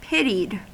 Ääntäminen
Ääntäminen US Haettu sana löytyi näillä lähdekielillä: englanti Käännöksiä ei löytynyt valitulle kohdekielelle. Pitied on sanan pity partisiipin perfekti.